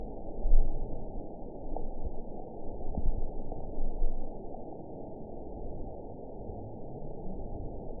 event 921740 date 12/18/24 time 07:52:59 GMT (4 months, 2 weeks ago) score 7.38 location TSS-AB03 detected by nrw target species NRW annotations +NRW Spectrogram: Frequency (kHz) vs. Time (s) audio not available .wav